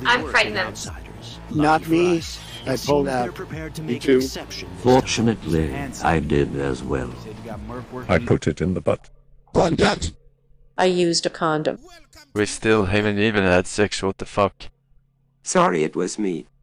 TTS Voices for Forsen’s stream
TTS-Voices